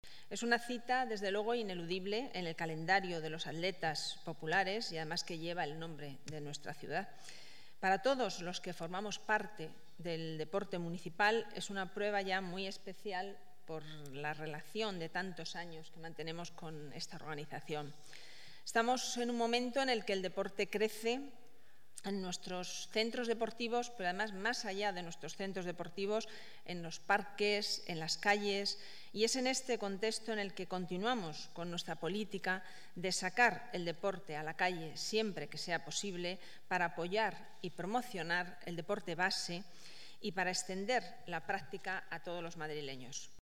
Nueva ventana:Declaraciones concejal delegada Deportes, Patricia Lázaro: presentación Medio Maratón Villa Madrid